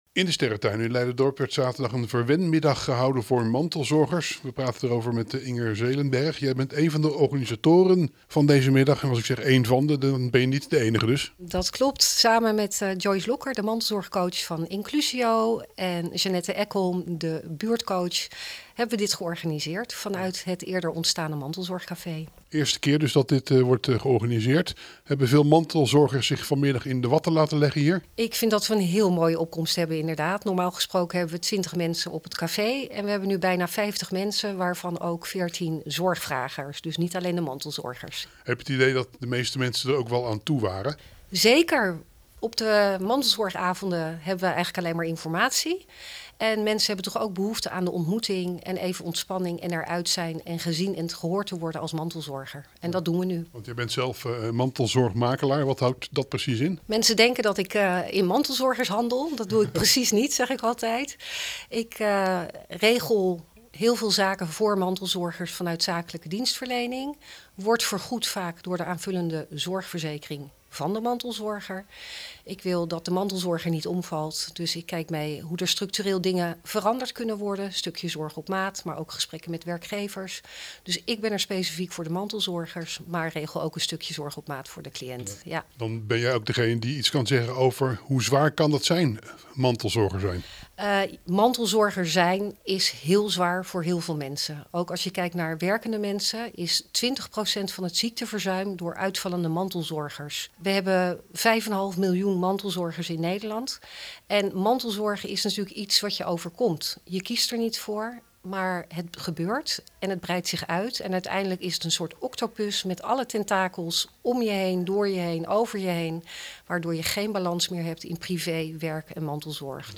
AUDIO: Verslaggever